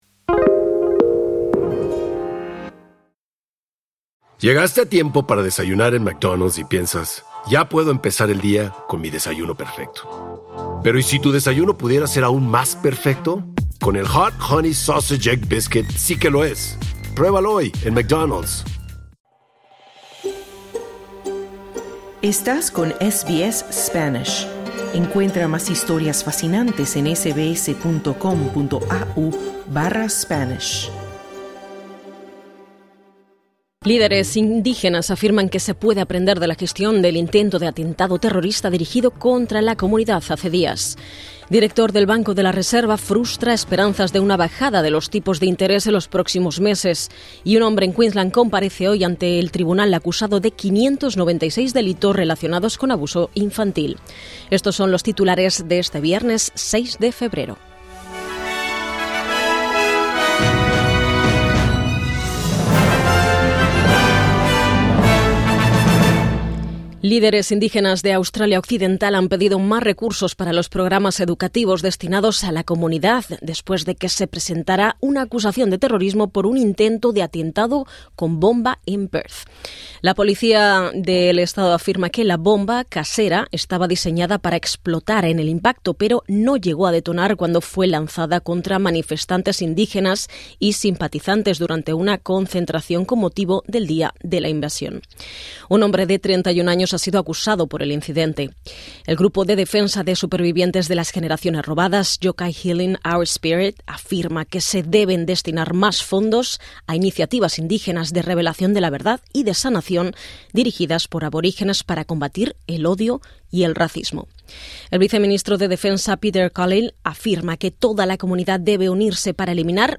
Boletín de noticias viernes 06/02/2026: El gobernador del Banco de la Reserva afirma que la inflación alcanzará los niveles objetivo fijados por el Banco Central en 2028, lo que frustra las esperanzas de una bajada de los tipos de interés en un futuro próximo.